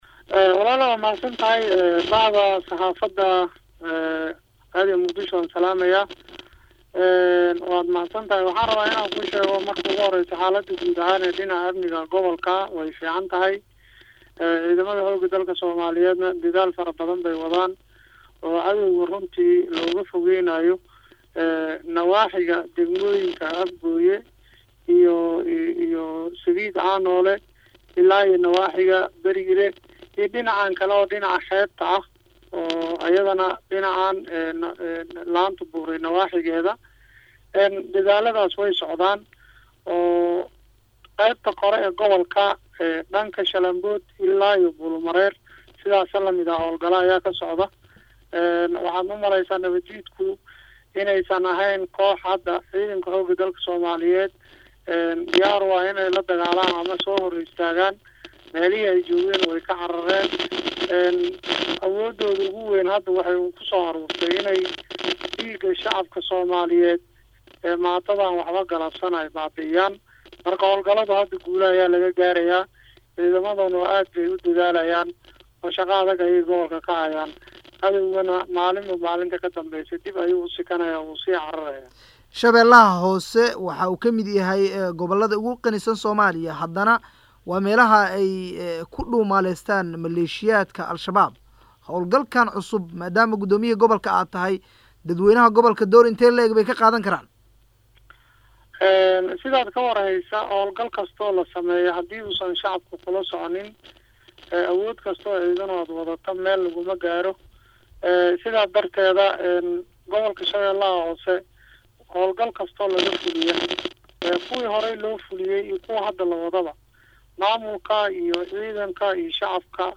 Waraysi-Gudoomiyaha-Gobolka-Shabeelaha-Hoose-Ibraahim-Najax.mp3